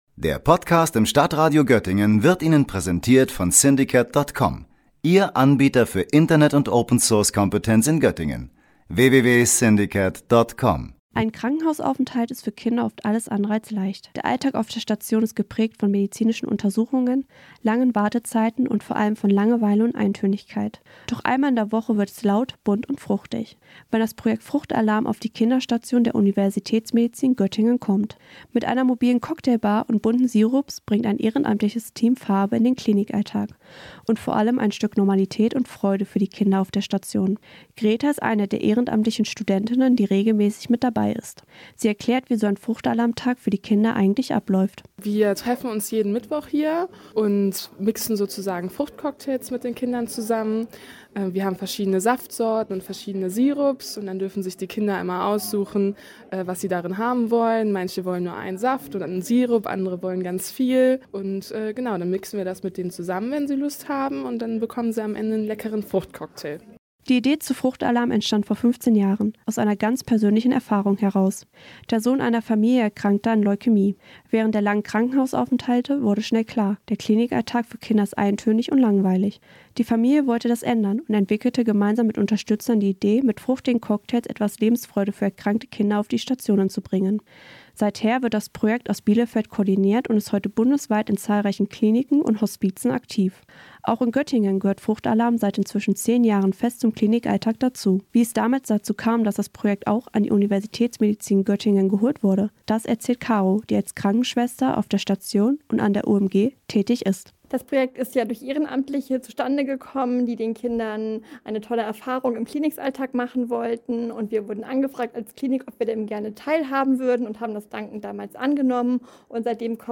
Beiträge > Zehn Jahre Fruchtalarm an der UMG - das steckt hinter dem Projekt - StadtRadio Göttingen